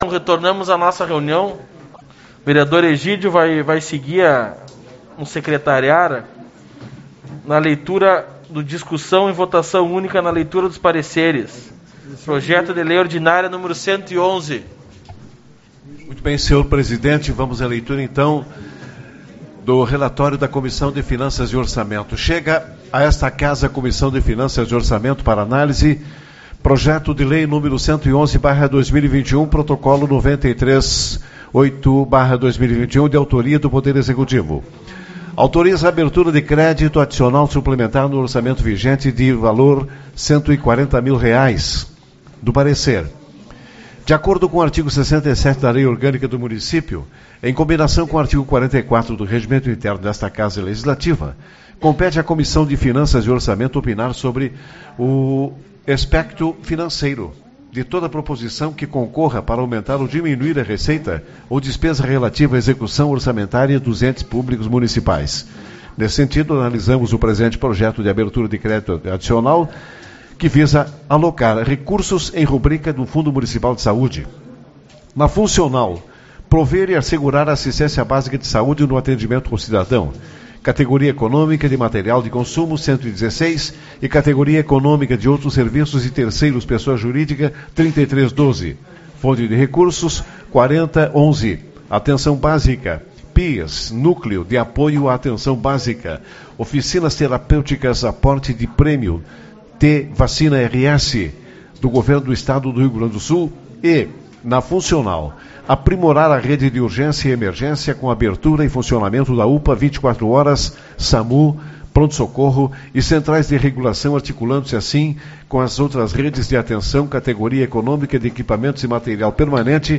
28/09 - Reunião Ordinária